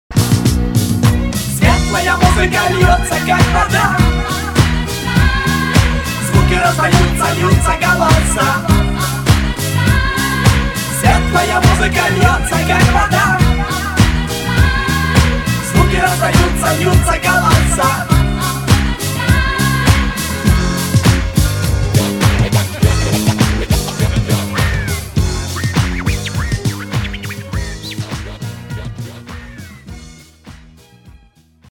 • Качество: 320, Stereo
Хип-хоп
добрые
светлые